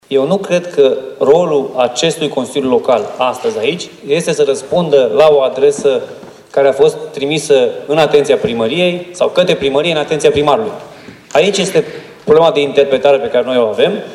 Președintele de ședință, consilierul PSD, Alfred Simonis, consideră că nu este rolul Consiliul Local să răspundă la o adresă trimisă în atenția primarului: